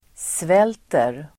Uttal: [sv'el:ter]